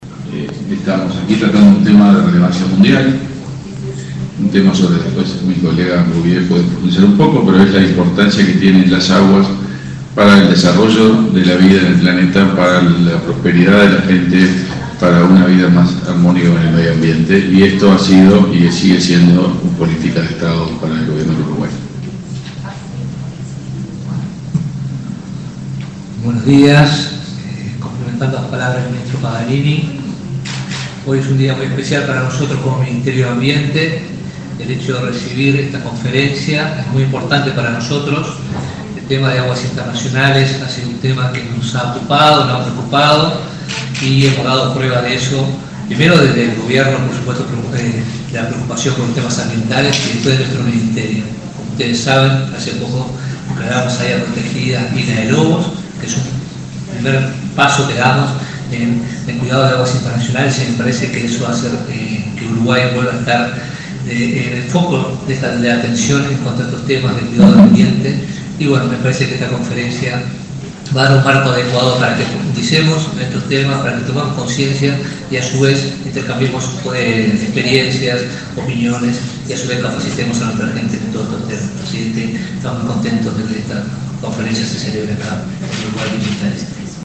Palabras del canciller Omar Paganini y el ministro de Ambiente, Robert Bouvier
El canciller Omar Paganini y el ministro de Ambiente, Robert Bouvier, participaron en la apertura de la Décima Conferencia Bienal de Aguas